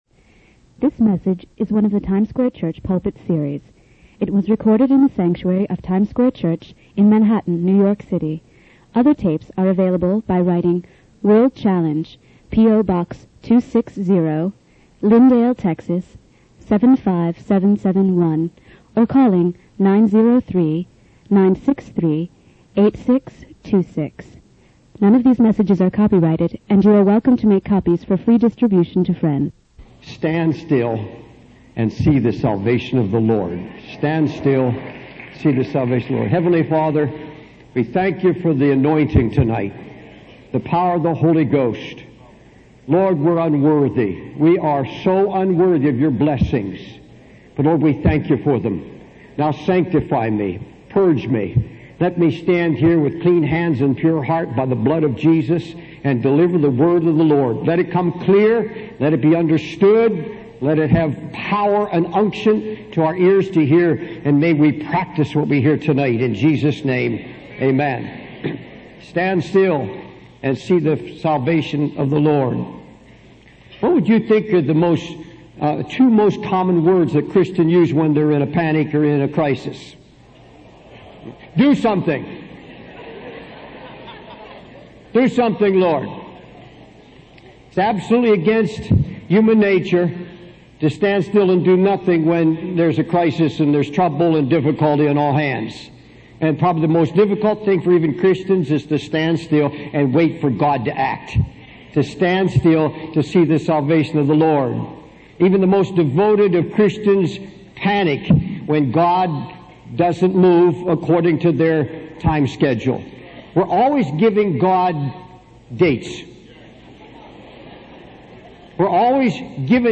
In this sermon, the preacher emphasizes the importance of standing still and trusting in the salvation of the Lord, even in the midst of difficult circumstances.